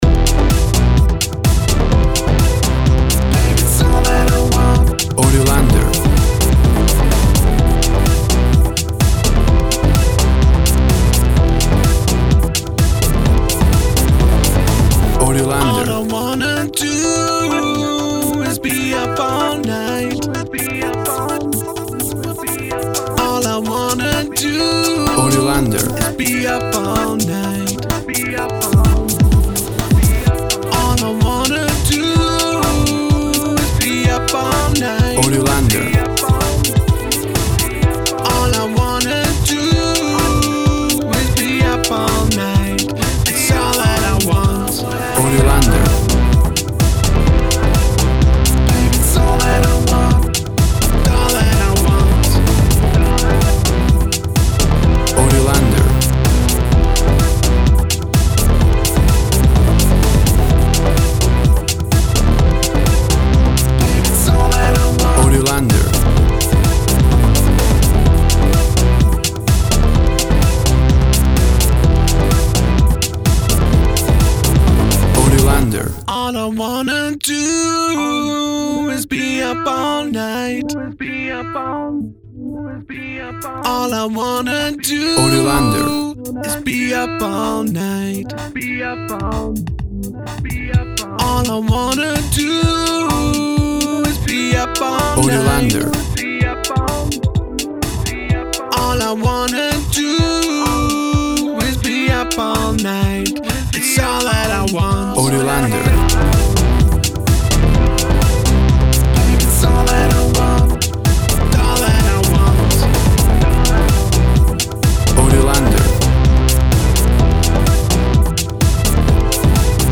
WAV Sample Rate 16-Bit Stereo, 44.1 kHz
Tempo (BPM) 127